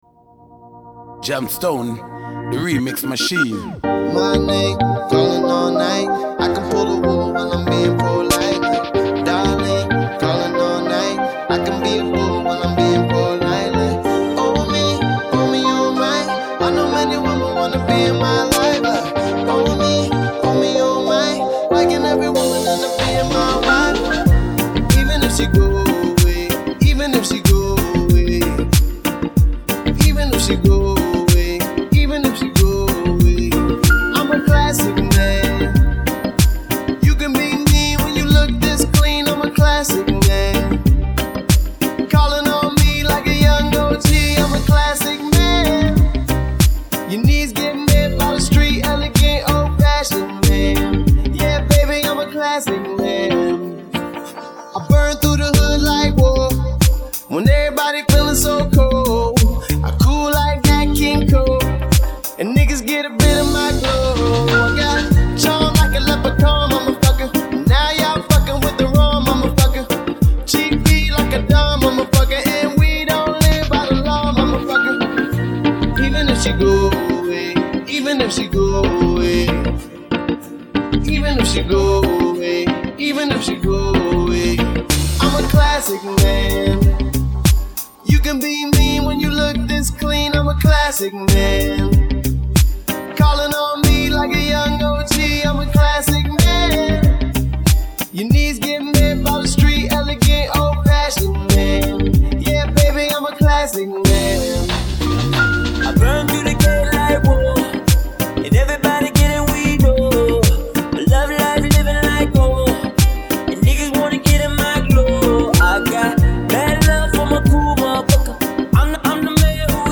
Vocal track
riddim